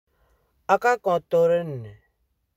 Lecture et prononciation
Lisez les phrases suivantes à haute voix, puis cliquez sur l'audio pour savoir si votre prononciation est la bonne.